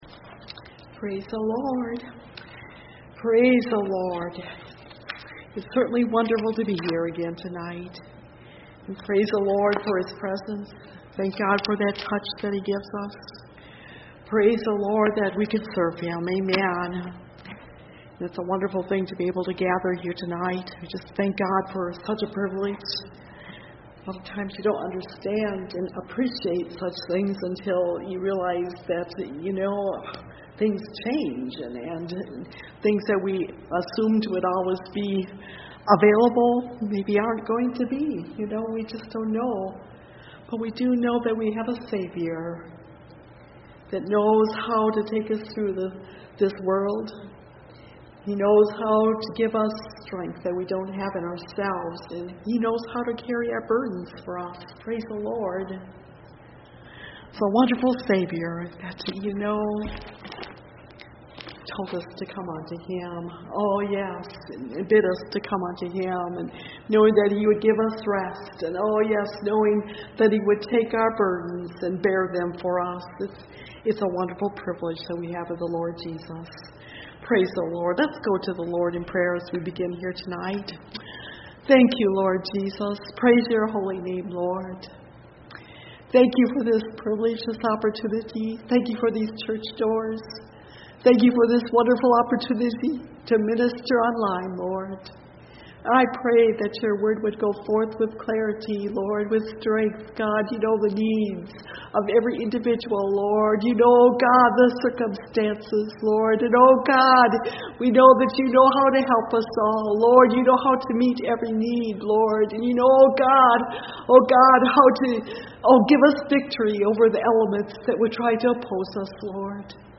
(Message Audio) – Last Trumpet Ministries – Truth Tabernacle – Sermon Library